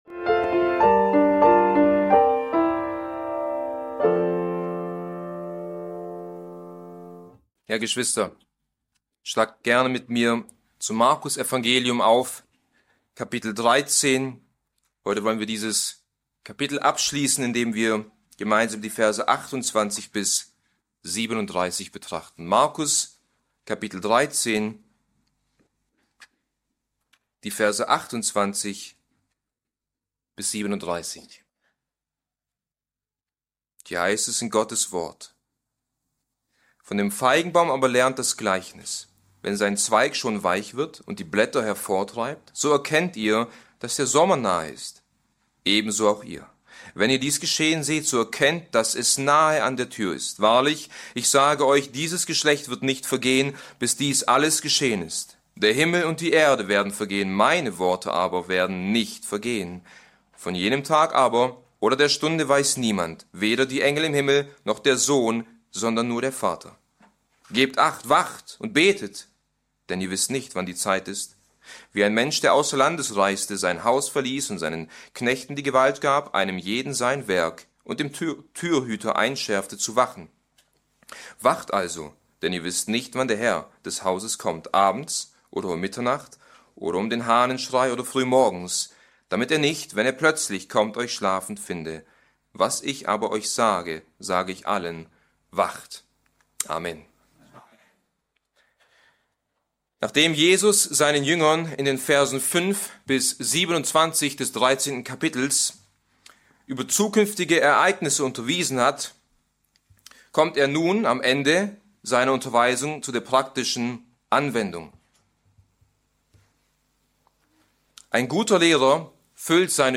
Bibeltreue Predigten